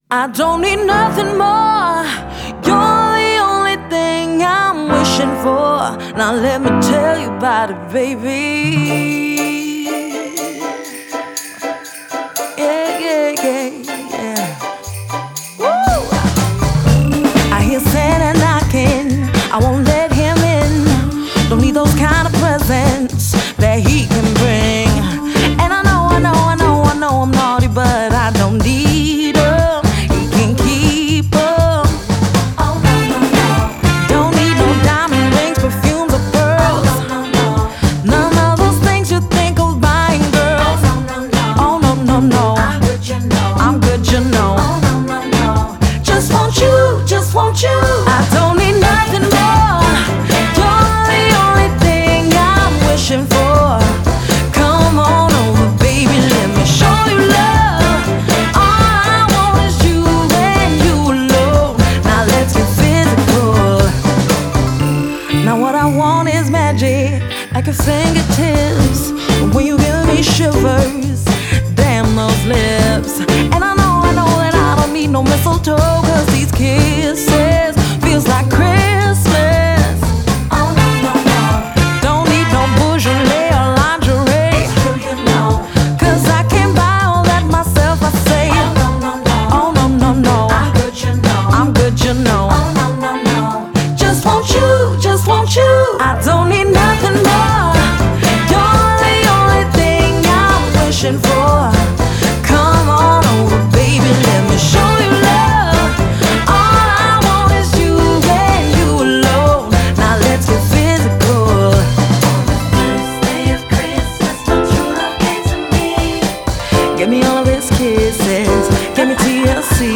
энергичная поп-песня
мощным вокалом и харизмой